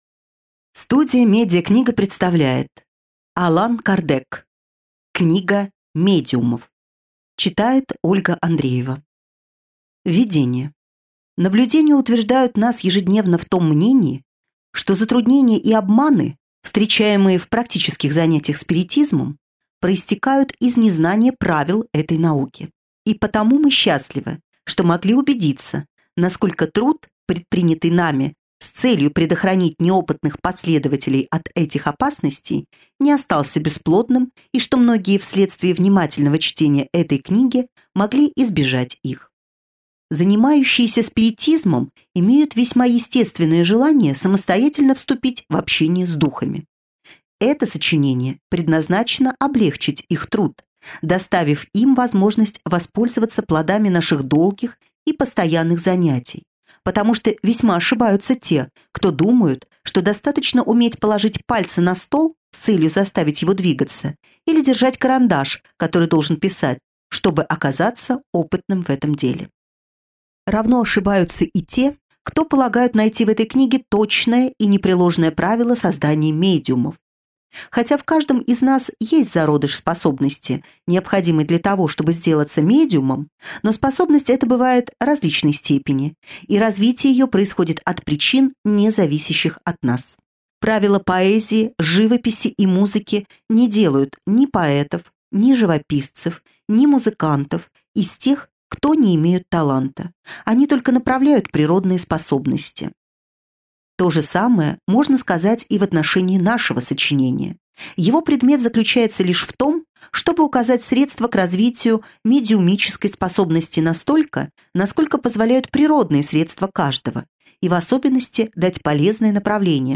Аудиокнига Книга медиумов | Библиотека аудиокниг
Прослушать и бесплатно скачать фрагмент аудиокниги